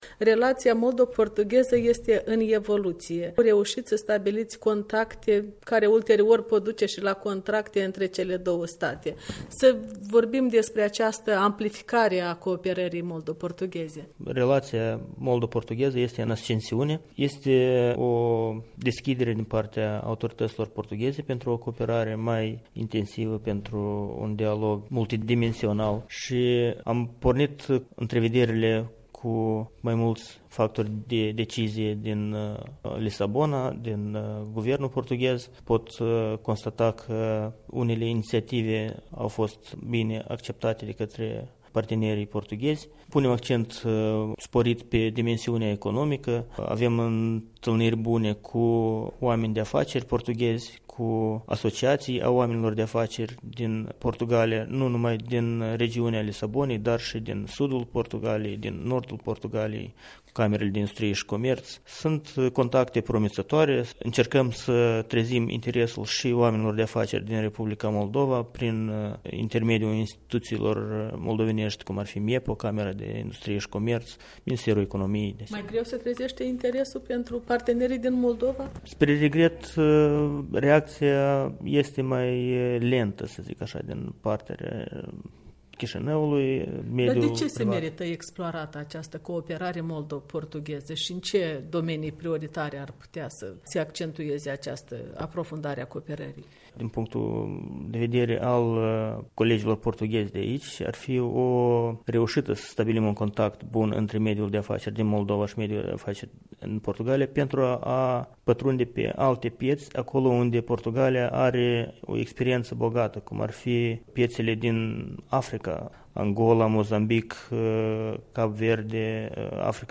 Interviu cu ambasadorul Republicii Moldova la Lisabona.